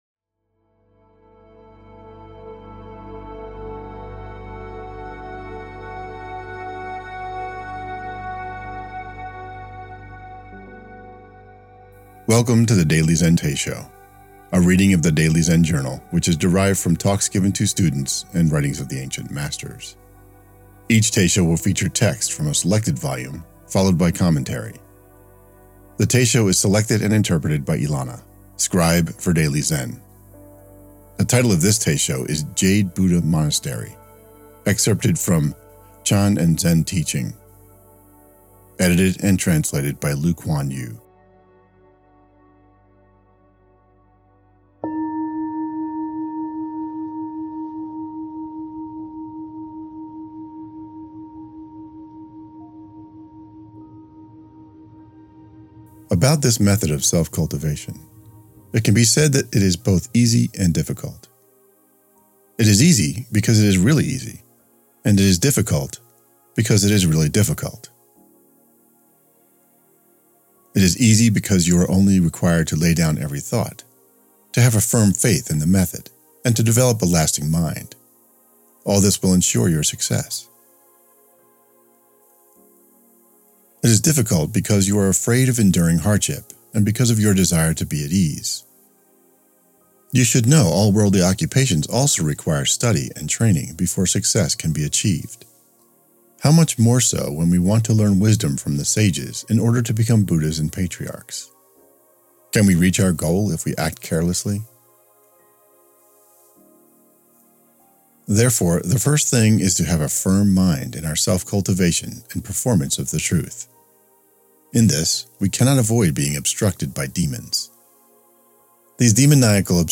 The Daily Zen Teisho